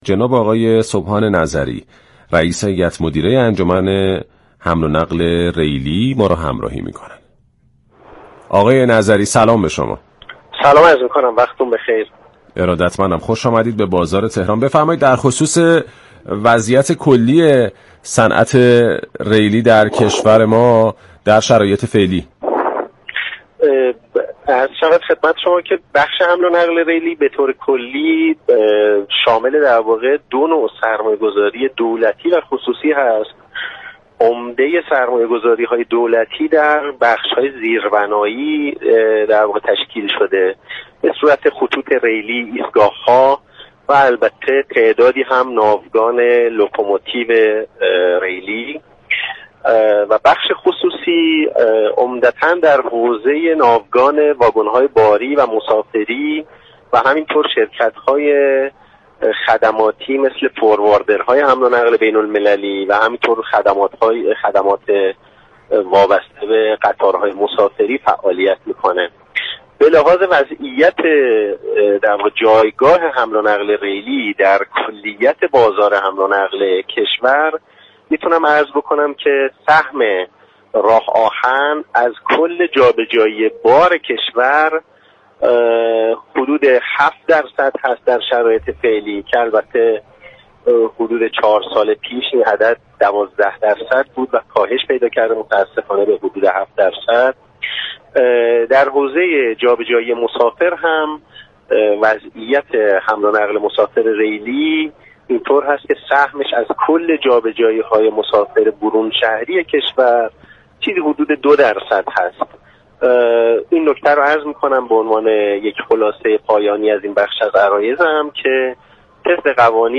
در گفت و گو با «بازار تهران» رادیو تهران